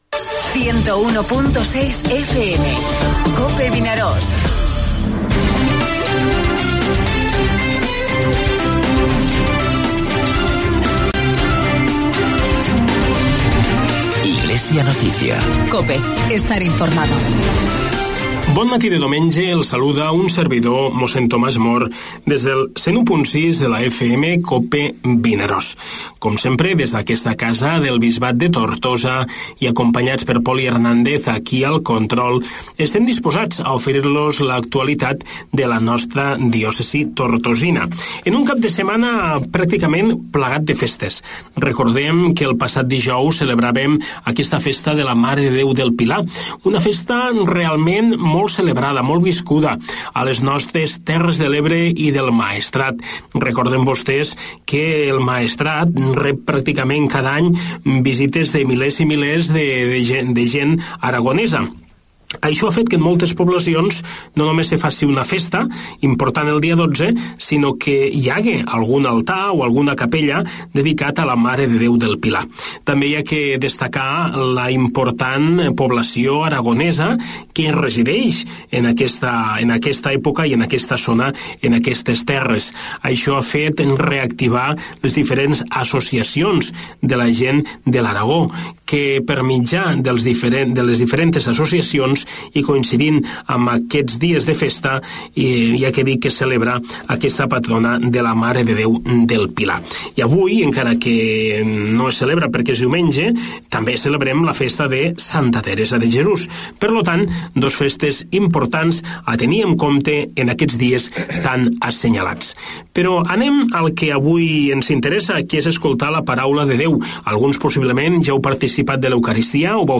AUDIO: Espai informatiu del Bisbat de Tortosa, tots els diumenges de 9.45 a 10 hores.